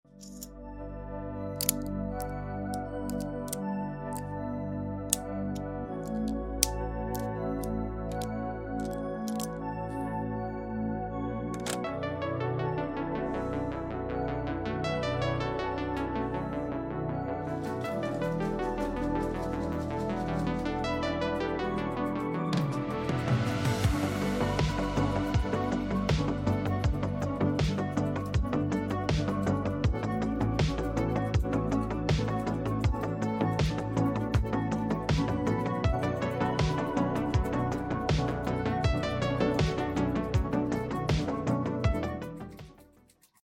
LEGO X Synthwave I Sound Effects Free Download